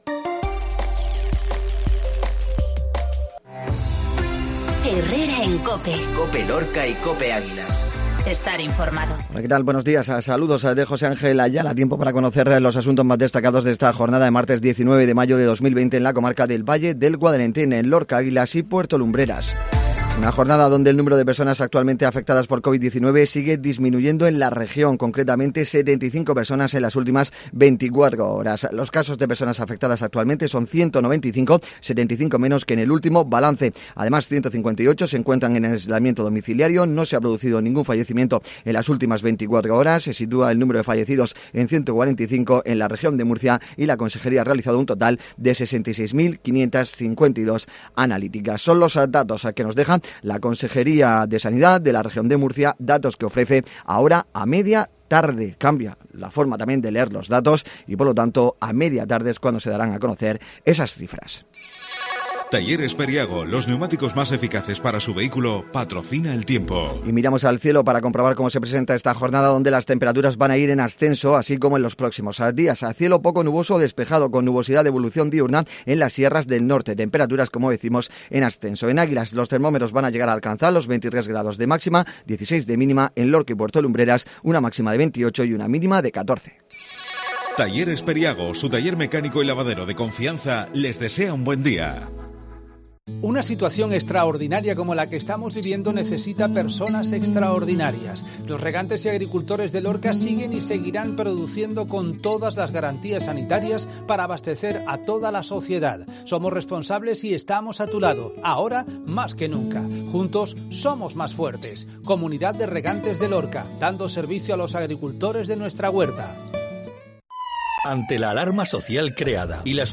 INFORMATIVO COPE MATINAL